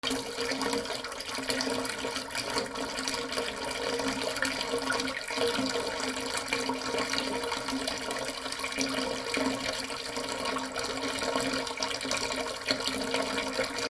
/ M｜他分類 / L30 ｜水音-その他
水音 水が水面に落ちる
『ジョボボ』